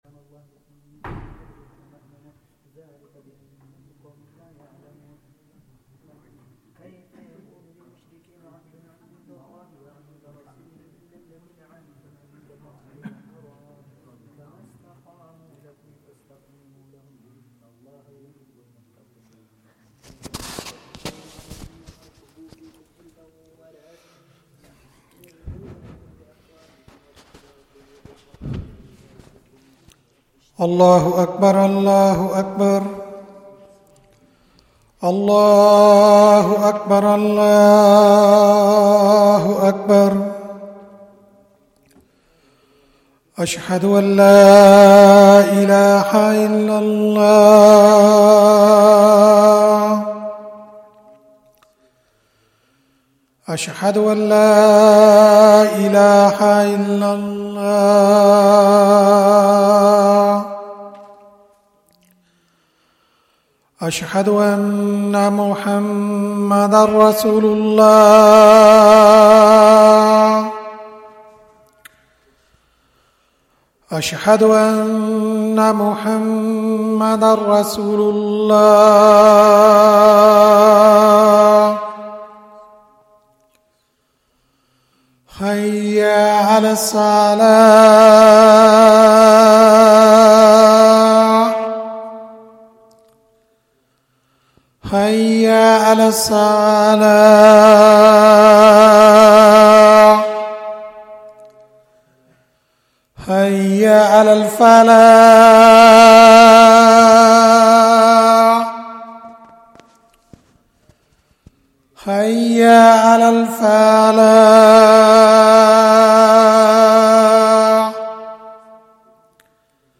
Salat al-Traweeh
Zakariyya Masjid Motherwell